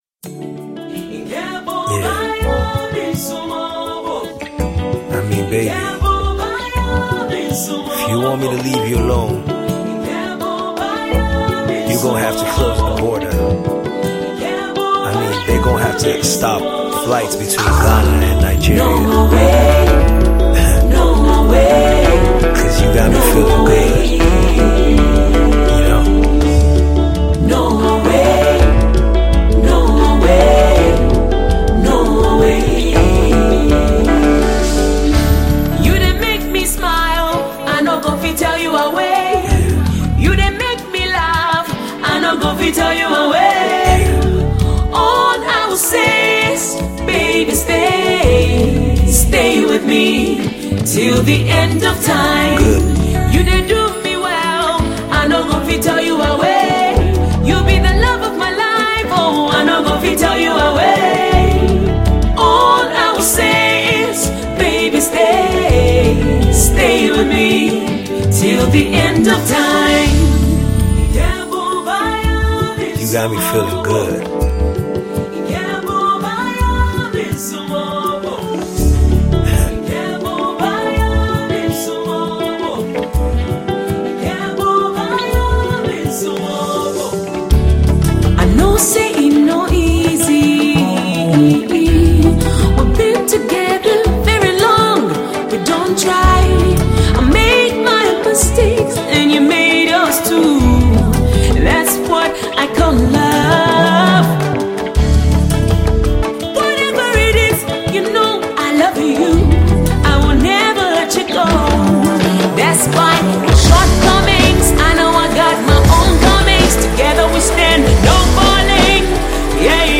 the vocal powerhouse